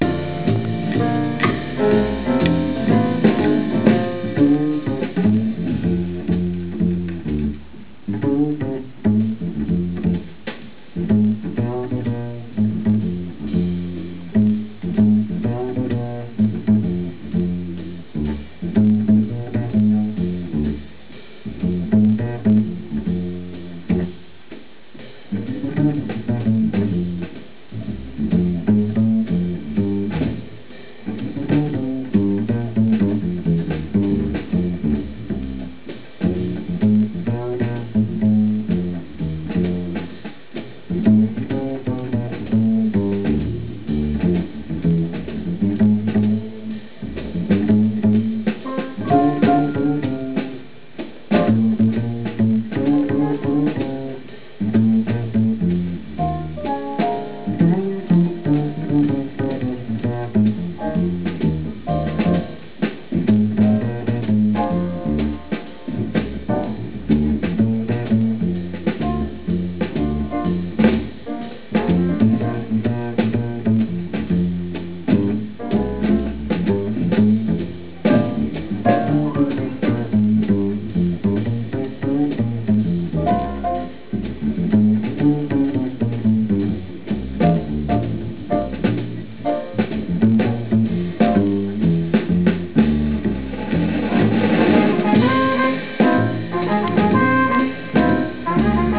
le chorus